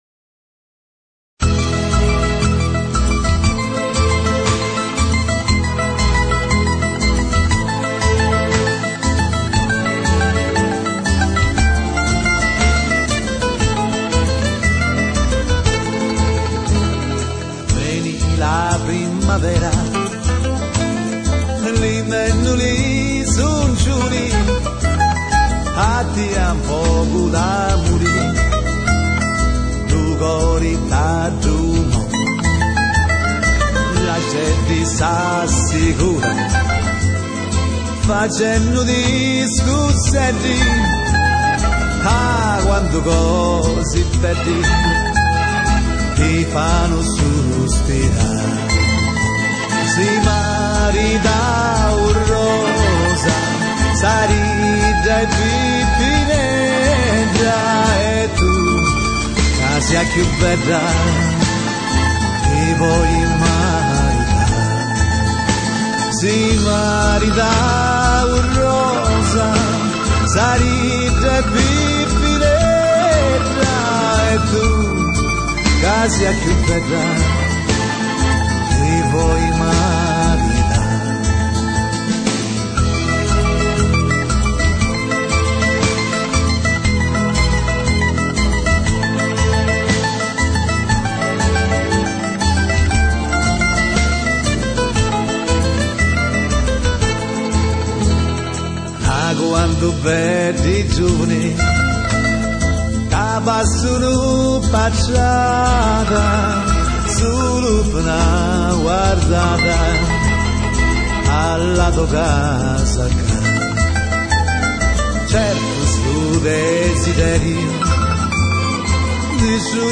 Понравилась первая, очень трогательное исполнение.
гитара
мандолина.